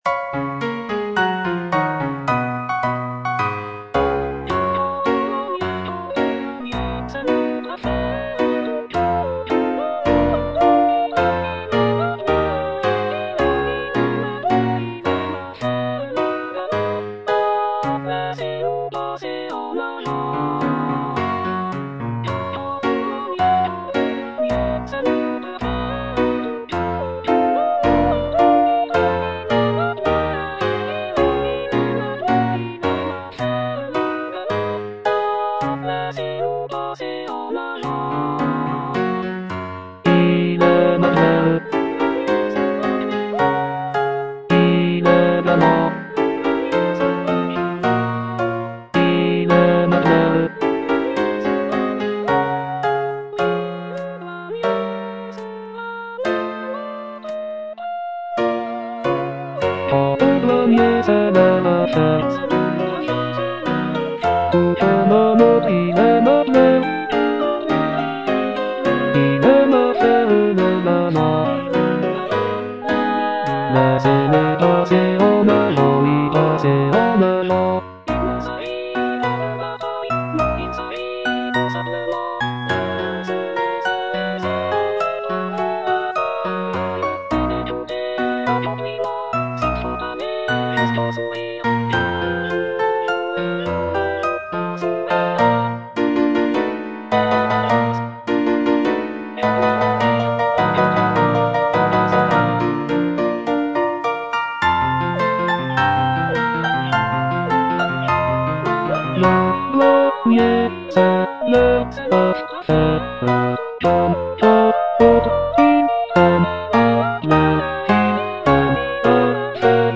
G. BIZET - CHOIRS FROM "CARMEN" Quant au douanier (bass II) (Voice with metronome) Ads stop: auto-stop